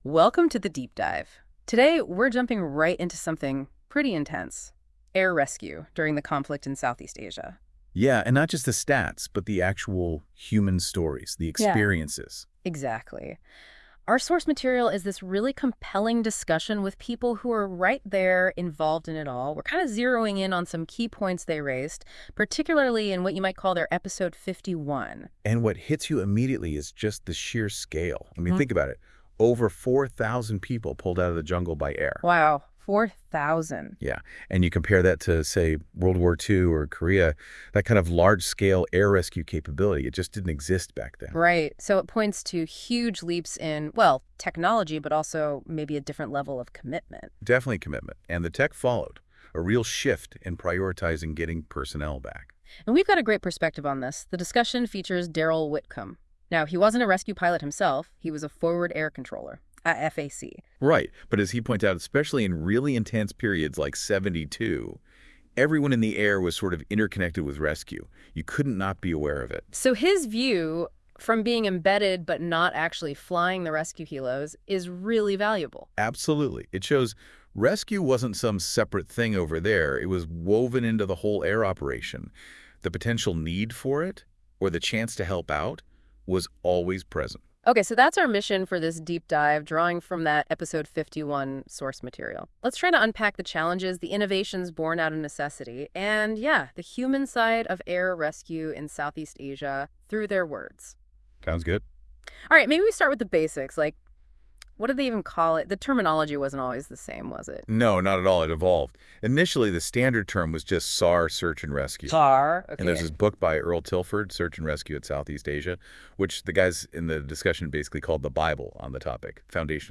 Episode 51 of Military Tales dives deep into air rescue operations in Southeast Asia, offering a unique perspective by featuring the personal stories of the participants. The episode brings together Sandys, Jolly drivers, and even some of those who were rescued to share their experiences. An impressive statistic highlighted early on is that Search and Rescue (SAR) forces in Southeast Asia pulled over 4,000 people out of the jungle.